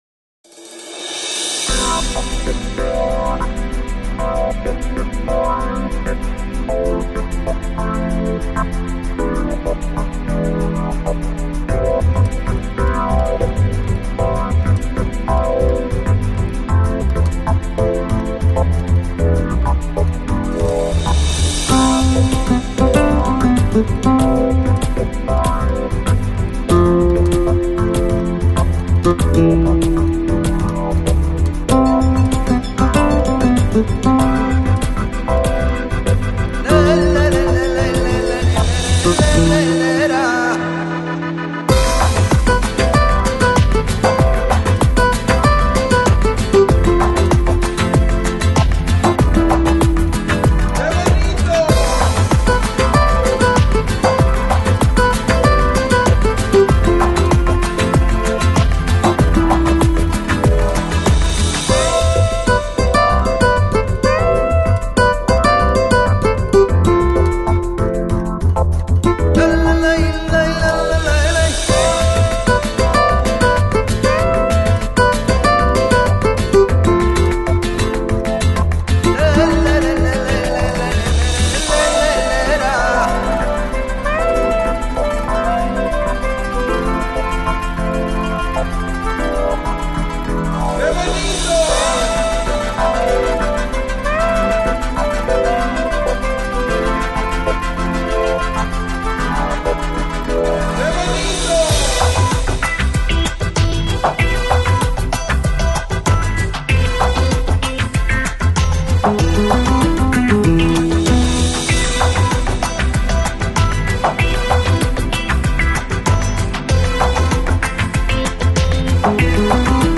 Electronic, Lounge, Chill Out, Downtempo, Guitar